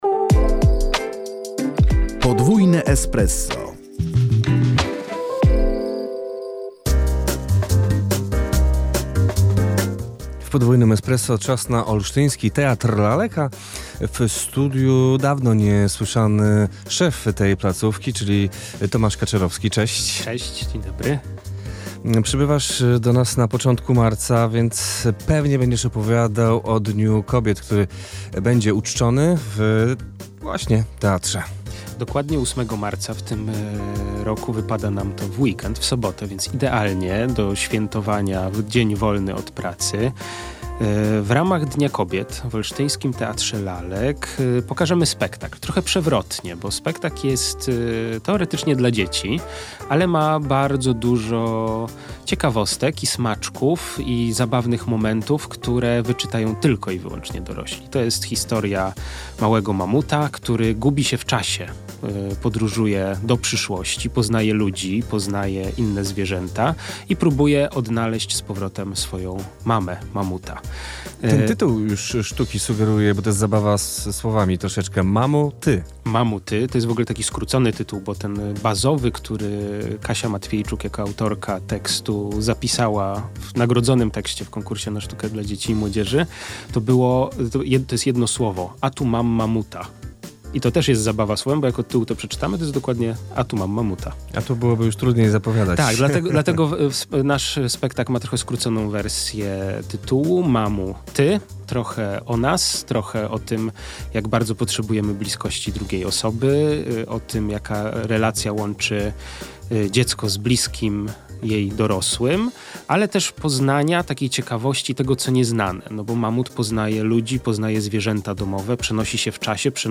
W drugiej części rozmowy